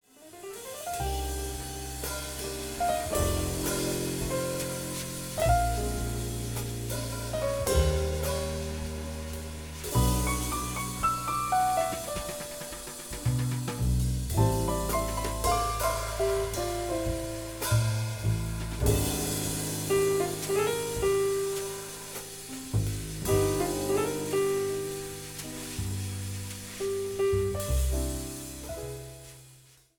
静寂な夜の空間を表現している。